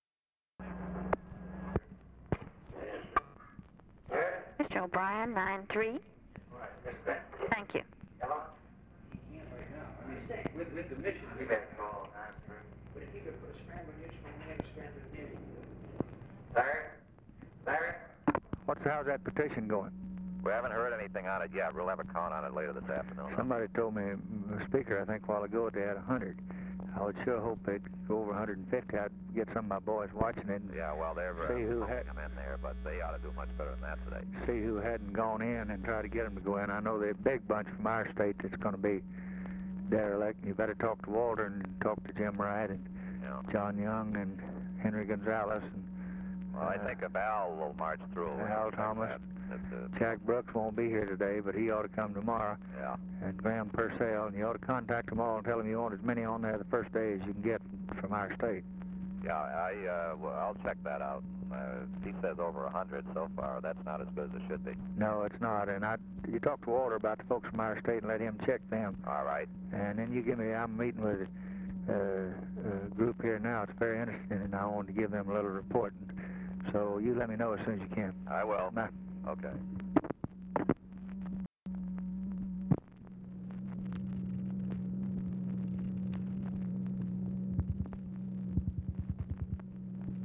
Conversation with LARRY O'BRIEN, December 9, 1963
Secret White House Tapes